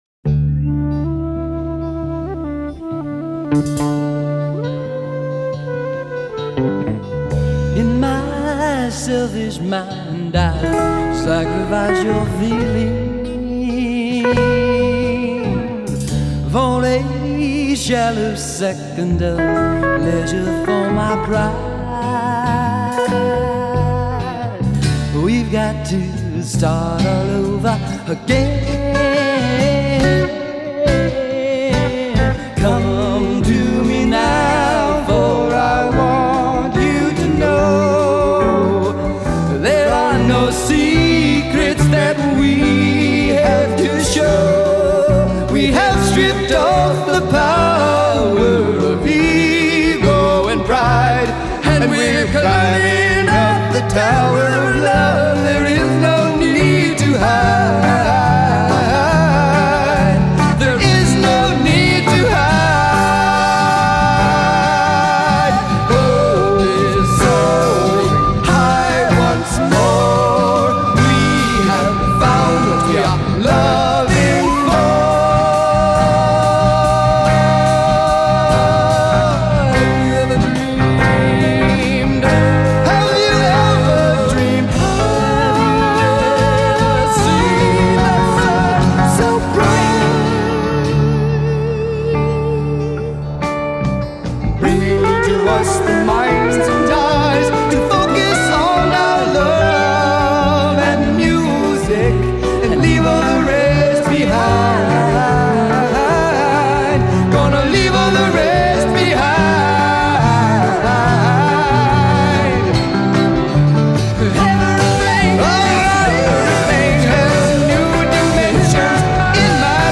we recorded it in the early 1970's
performed by The Joy Urchins
Very hopeful...a cresendo of hope....